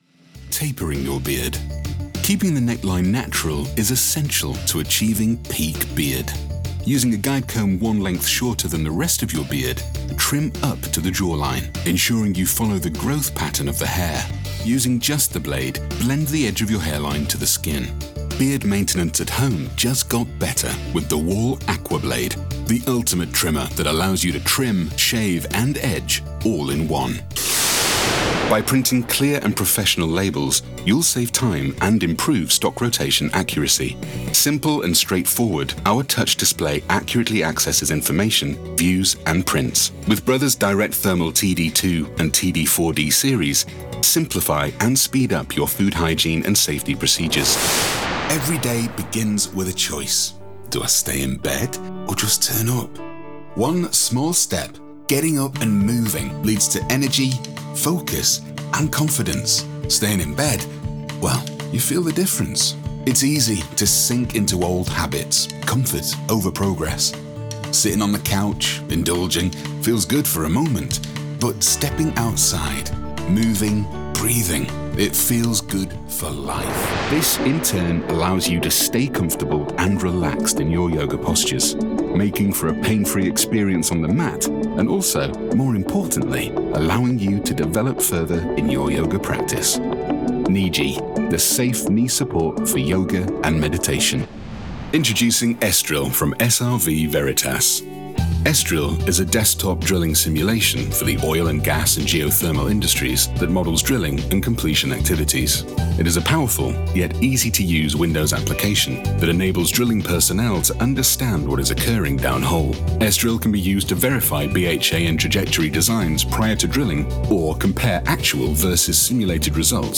From Northern accent to well spoken RP and everything in between
Explainer
VOICE-REEL-EXPLAINER-17.9.25.mp3